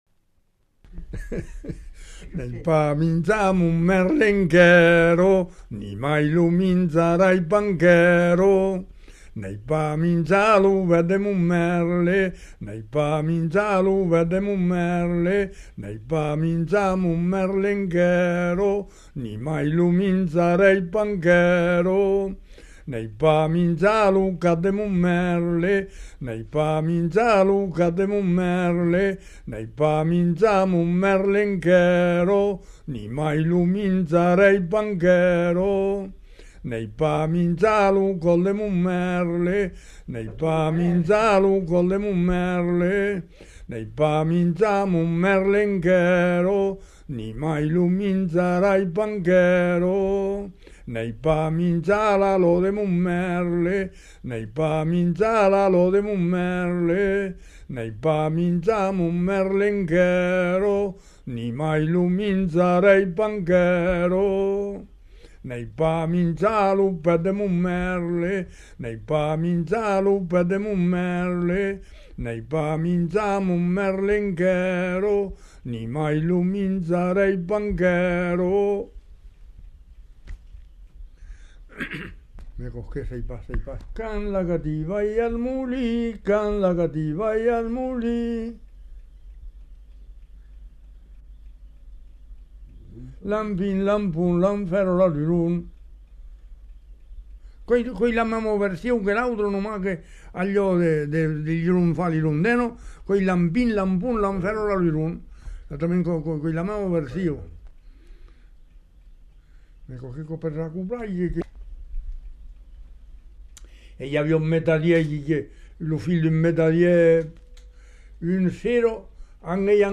Aire culturelle : Haut-Agenais
Lieu : Parranquet
Genre : chant
Effectif : 1
Type de voix : voix d'homme
Production du son : chanté